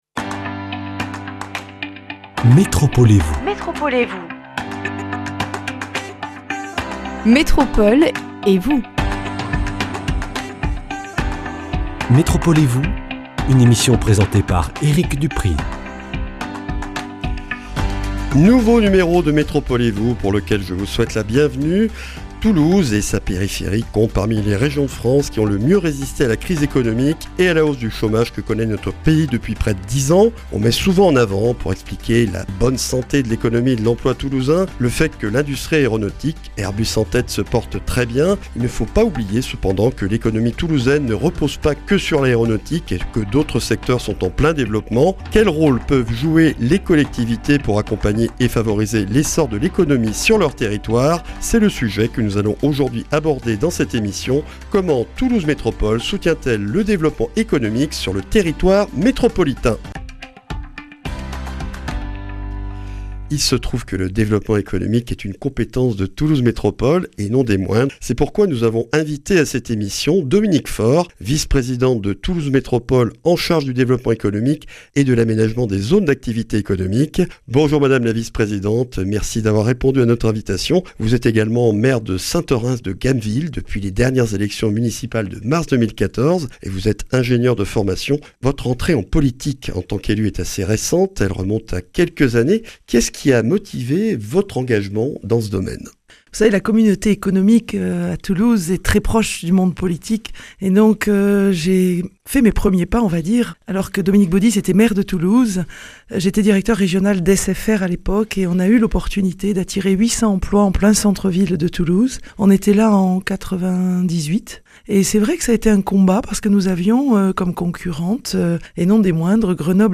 Dominique Faure, maire de Saint-Orens-de-Gameville, est également Vice-présidente de Toulouse Métropole en charge du Développement économique et de l’Aménagement des zones d’activités économiques. Elle est l’invitée de ce numéro pour un échange autour de l’action de Toulouse Métropole en faveur du développement économique de son territoire.